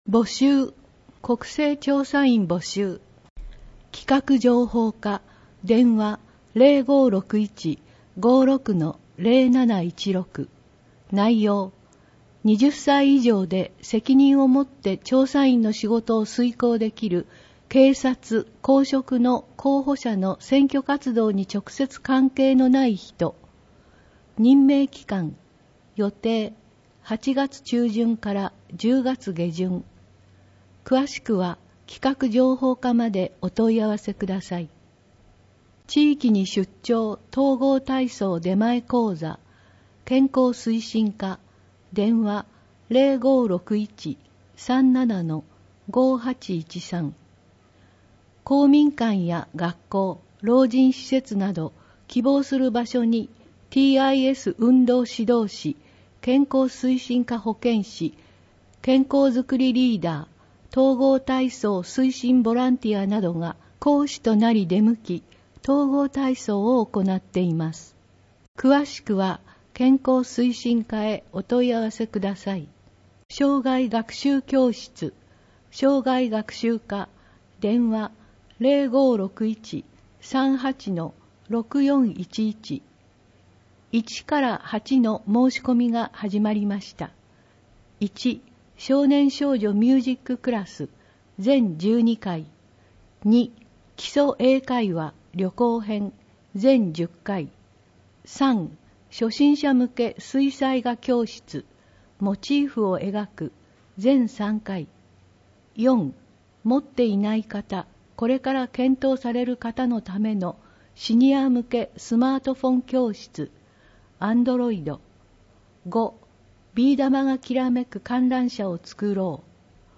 広報とうごう音訳版（2020年4月号）